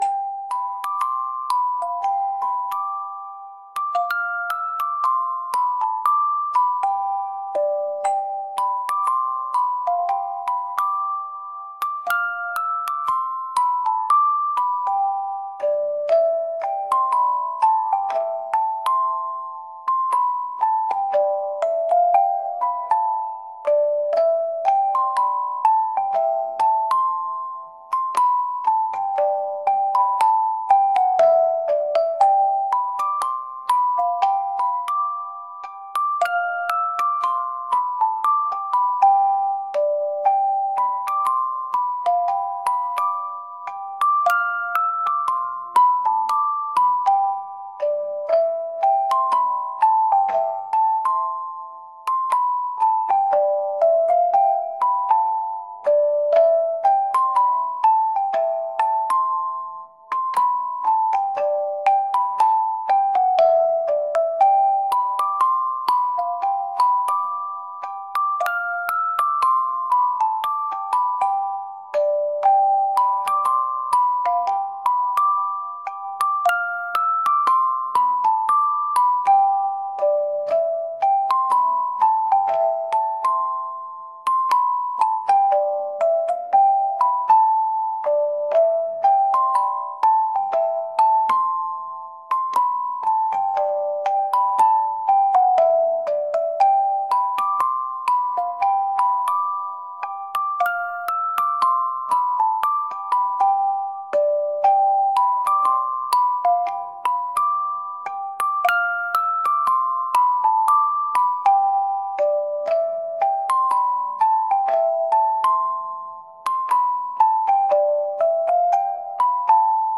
「オルゴール」